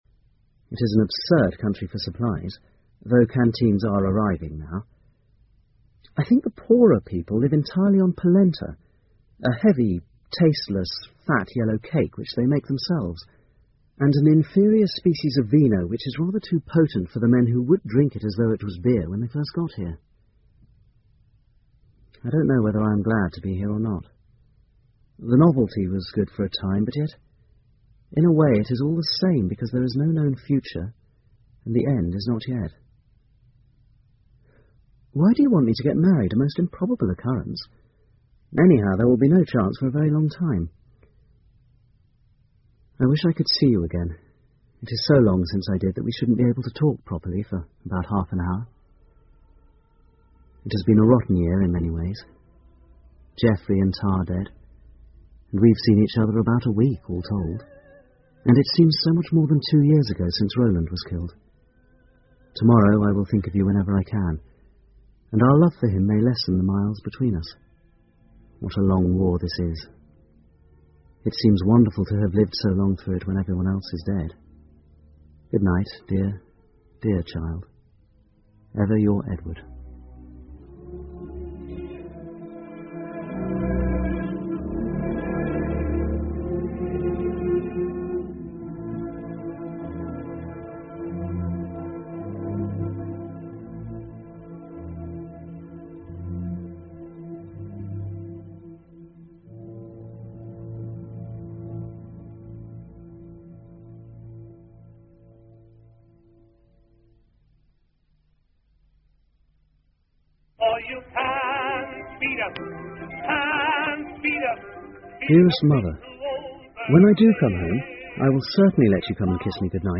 英文广播剧在线听 Letters from a Lost Generation 薇拉·布里坦一战书信集 36 听力文件下载—在线英语听力室